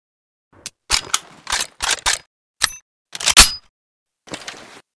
sks_reload_full.ogg